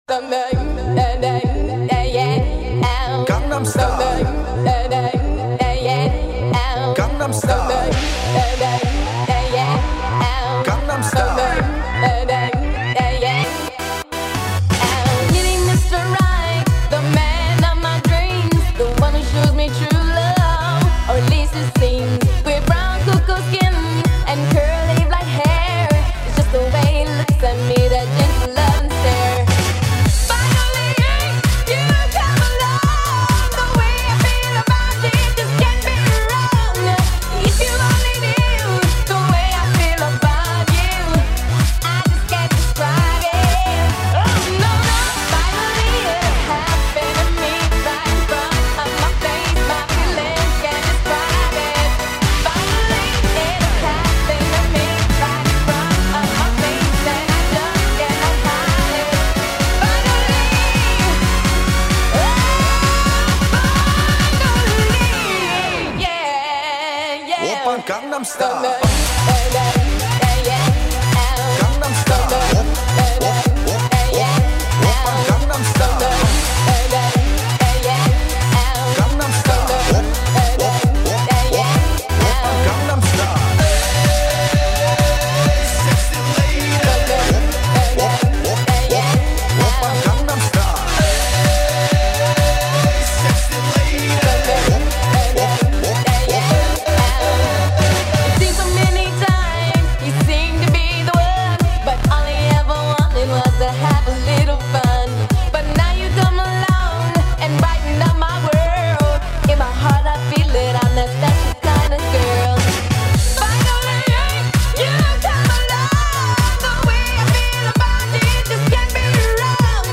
tema de club actual
Acapella
Instrumental & Acapella